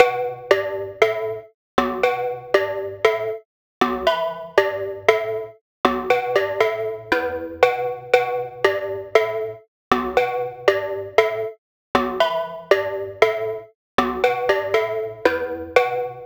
• Essentials Key Pop 9 118 bpm.wav
SC_Essentials_Key_Pop_9_118_bpm_YSy_wti.wav